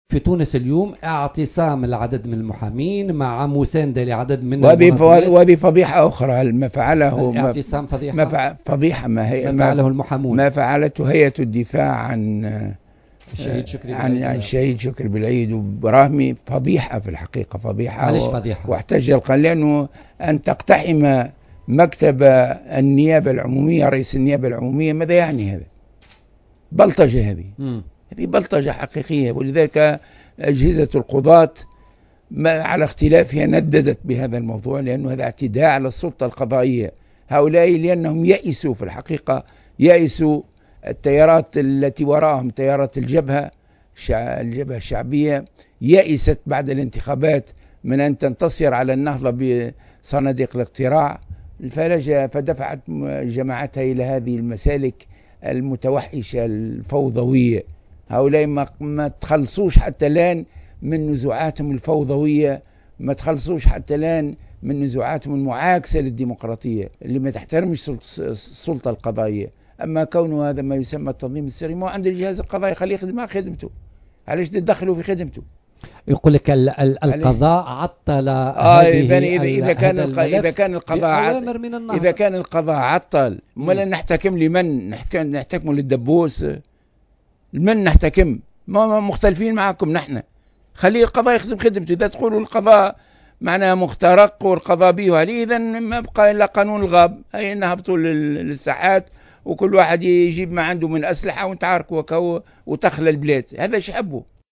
و اعتبر "الغنوشي" ضيف "حصّة بوليتيكا" اليوم الاثنين على موجات الجوهرة "أف أم"، أنّ ما اقترفته هيئة الدفاع عن الشهيديْن شكري بلعيد و محمد البرامهمي فضيحة، مشيرا إلى أنّ اقتحام مكتب رئيس النيابة العمومية "بلطجة حقيقية" حسب تعبيره، مذكرّا بموقف القضاة الرافضين لهذا التحرّك الاحتجاجي.